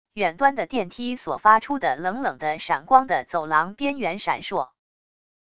远端的电梯所发出的冷冷的闪光的走廊边缘闪烁wav音频